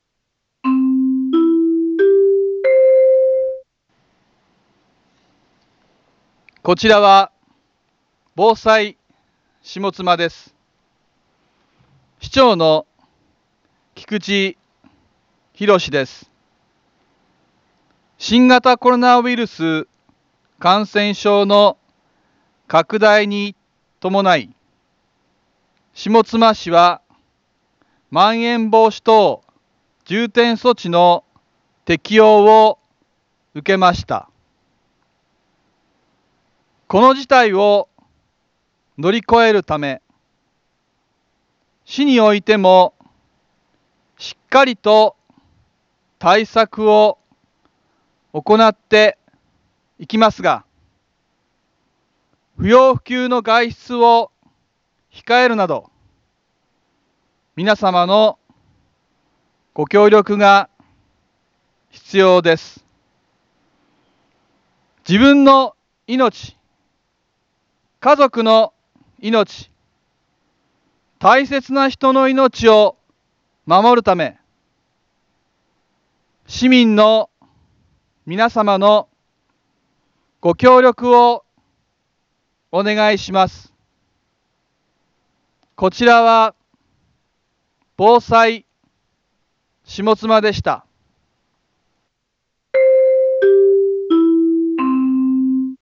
Back Home 一般放送情報 音声放送 再生 一般放送情報 登録日時：2021-08-08 18:31:39 タイトル：新型コロナウイルス感染症にかかる注意喚起 インフォメーション：こちらは、防災下妻です。